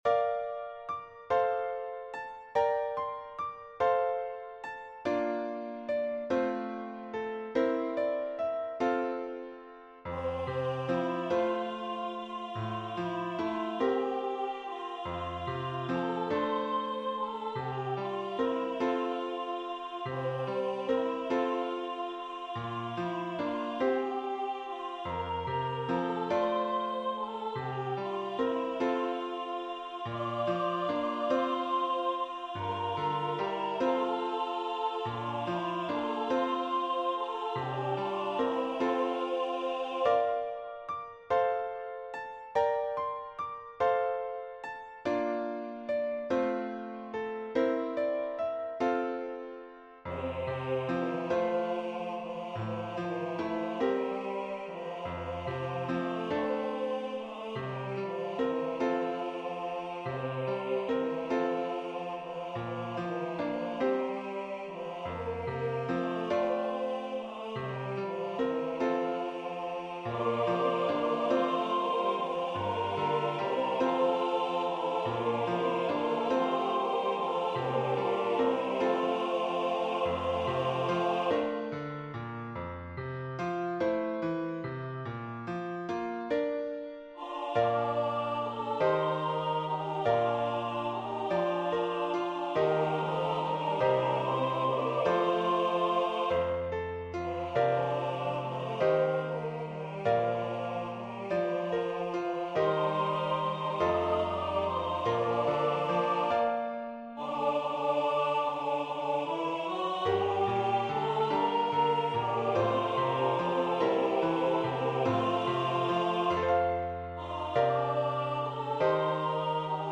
SATB
I thought it would be fun to reimagine Once in Royal David's City in 6/8 instead of 4/4.
Voicing/Instrumentation: SATB We also have other 35 arrangements of " It Came Upon a Midnight Clear ".